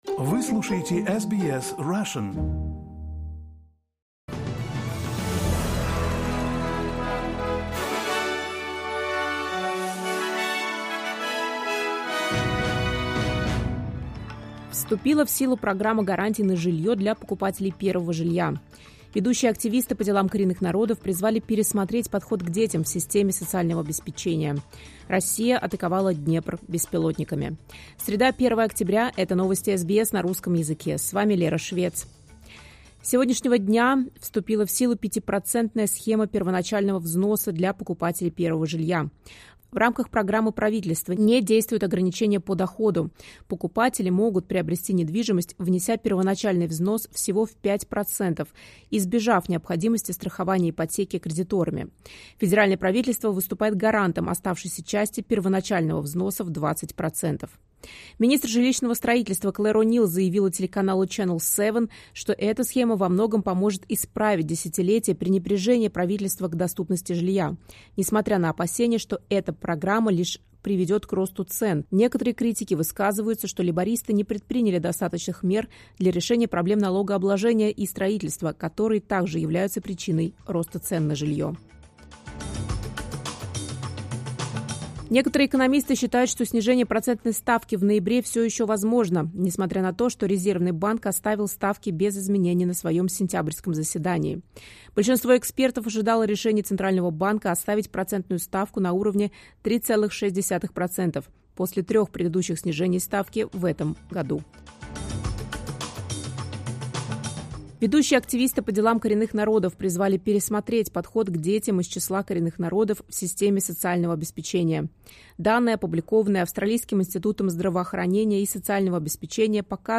Новости SBS на русском языке — 01.10.2025